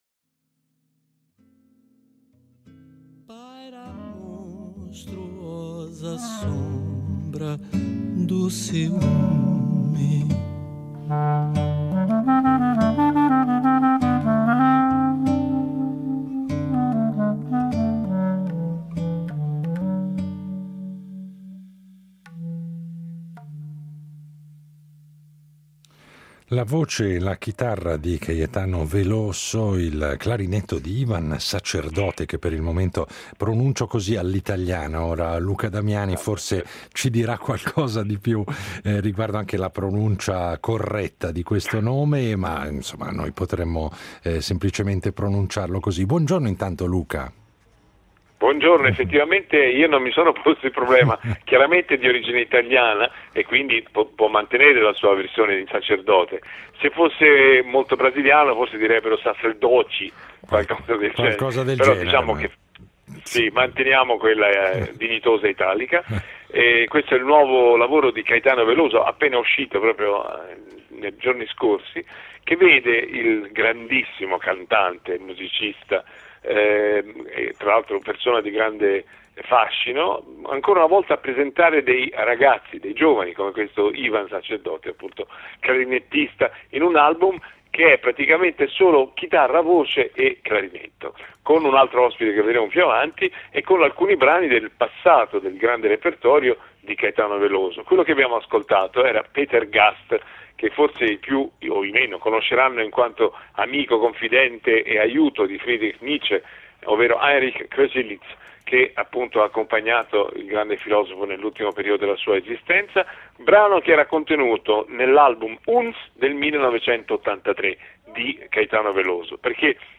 ciò che si ascolterà saranno solo la voce e la chitarra di uno dei più grandi autori e interpreti della MPB e il clarinetto di un giovane strumentista chiamato a punteggiare canzoni bellissime.